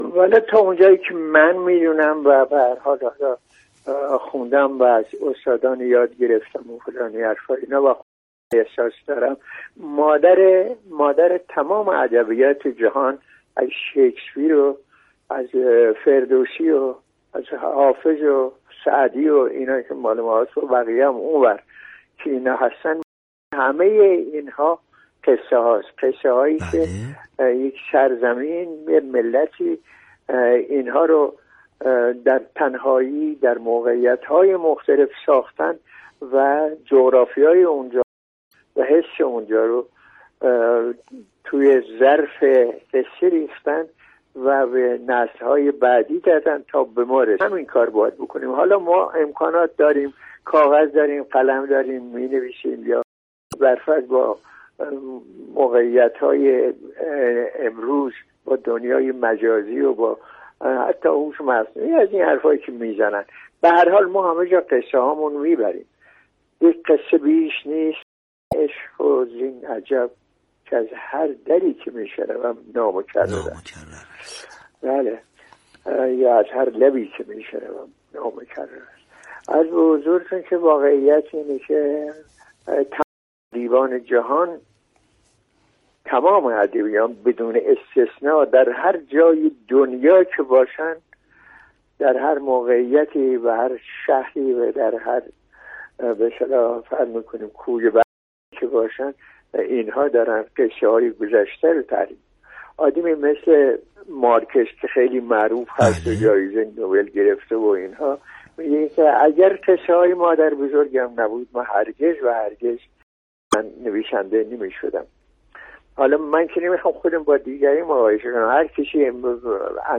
همان صدای گرم و همان نوازش مهربان اصوات بیانش؛ اما اینبار آرام‌تر، بریده‌تر و ظریف‌تر!
با همان صدای آرام‌تر از همیشه که نوایی از سپری کردن دوران نقاهت عمل جراحی داشت، آرام خندید و دل به این گفت‌وگو داد.